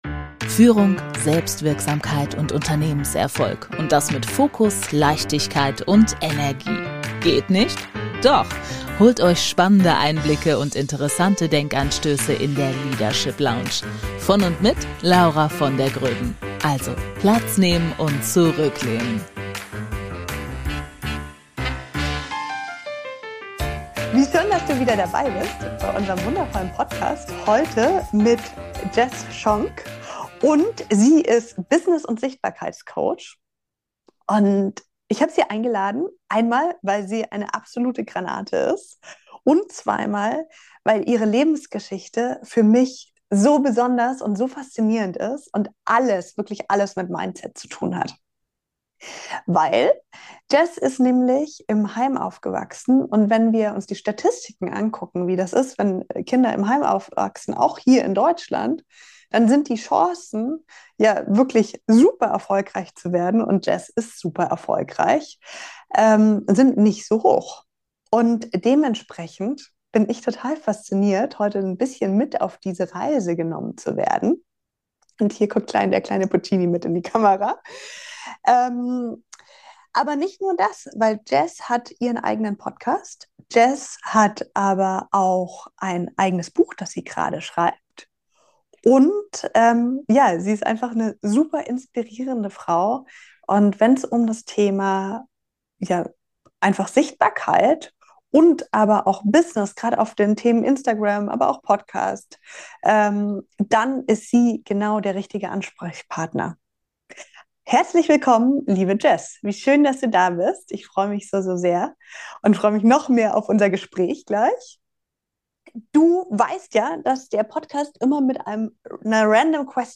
Ein Gespräch über Resilienz, Klarheit und den Mut, auch unbequeme Wahrheiten auszusprechen.